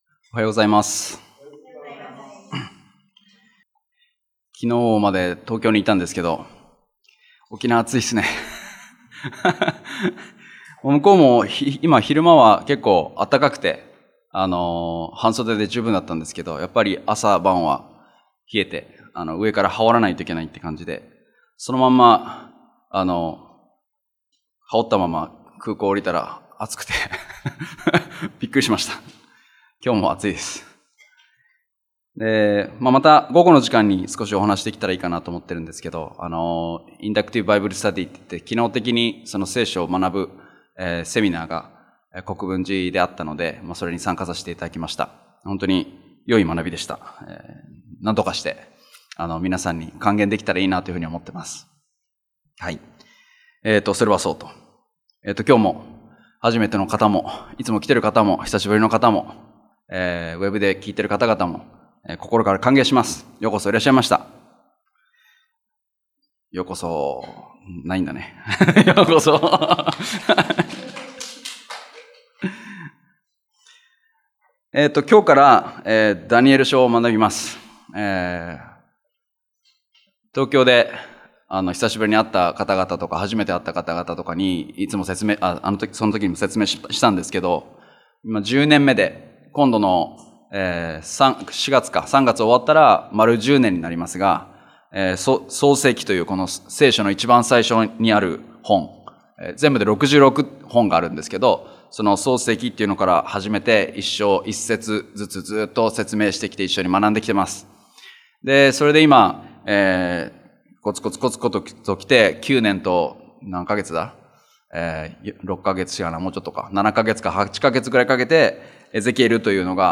聖書メッセージ
礼拝やバイブル・スタディ等でのメッセージを聞くことができます。